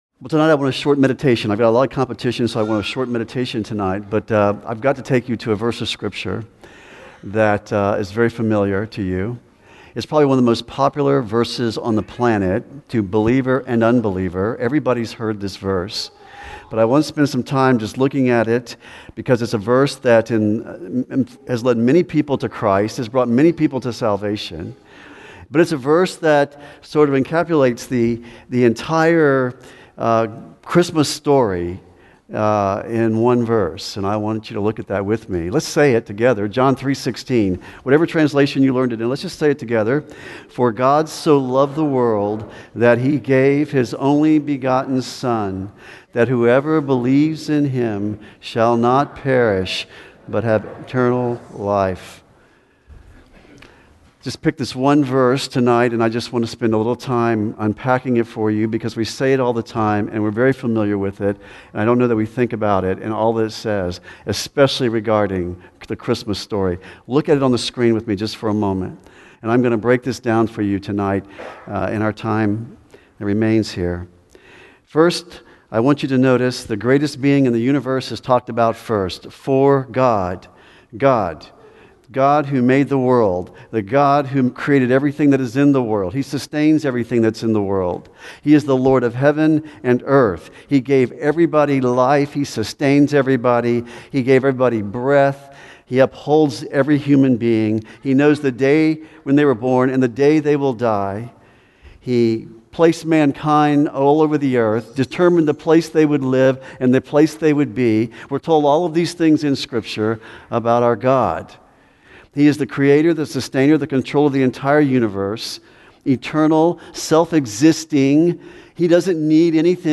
John 3:16 One of the most popular verses on the planet to both believers and unbelievers, John 3:16 is referenced when the discussion is about salvation. In this Christmas Eve message